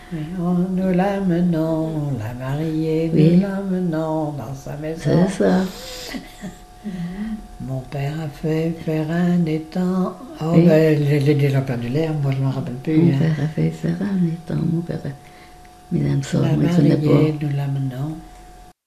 gestuel : à marcher
circonstance : fiançaille, noce
Genre laisse
Pièce musicale inédite